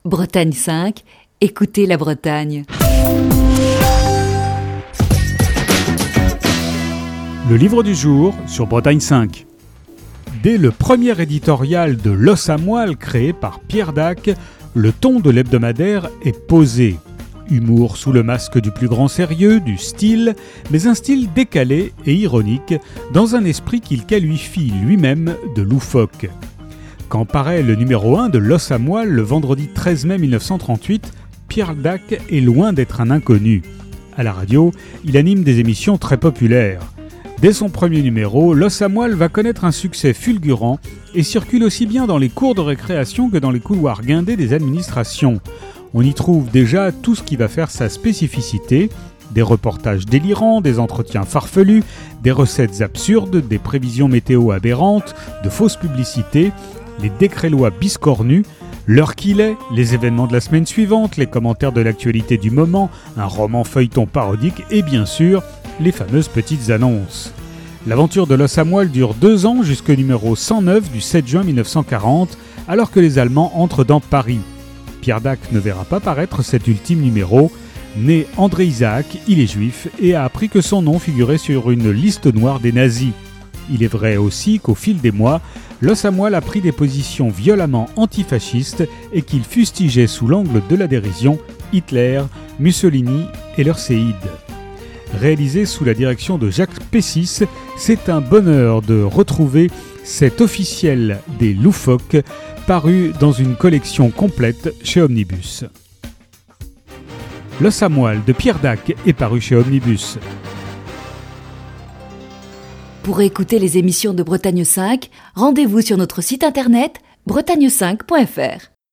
Chronique du 21 octobre 2020.